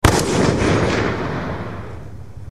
Hiệu ứng âm thanh DSR SLOW MO - Tải Mp3
Click vào đây để tải âm thanh DSR SLOW MOL về máy, hiệu ứng âm thanh dùng để để edit video, dựng video